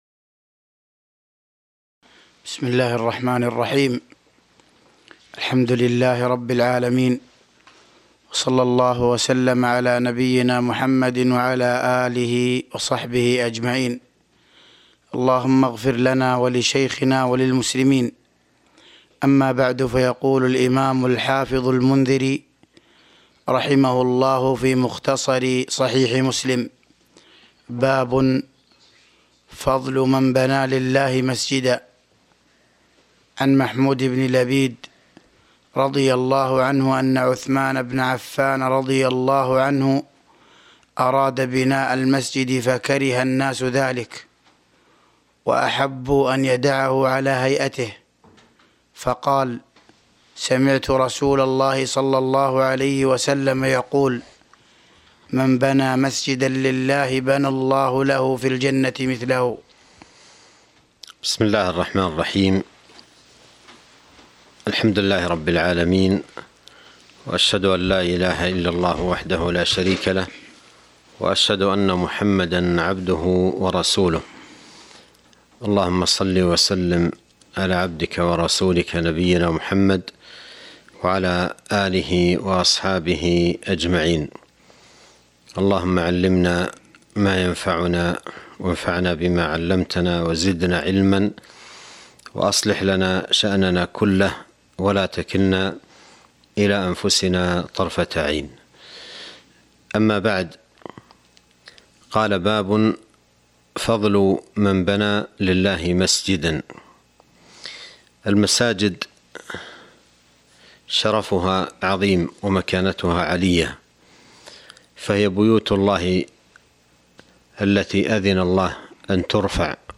تاريخ النشر ٢١ ربيع الثاني ١٤٤٢ هـ المكان: المسجد النبوي الشيخ